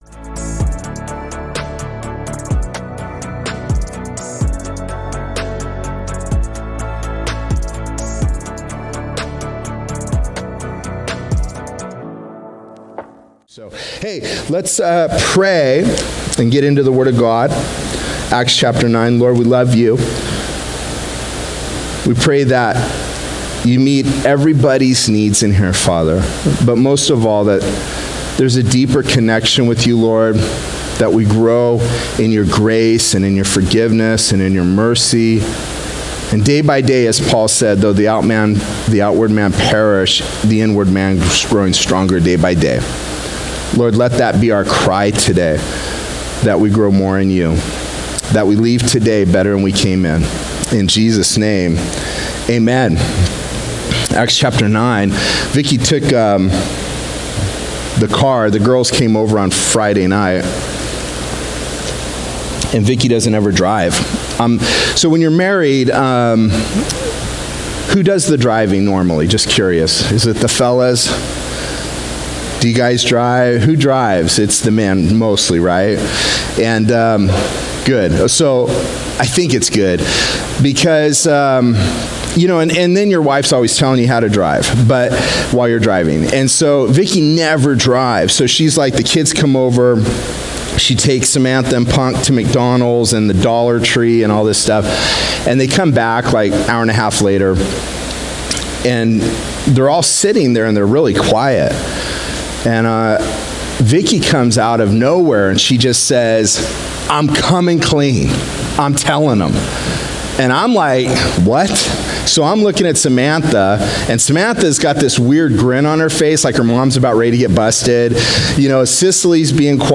Sermons Archive - Page 3 of 47 - Ark Bible Church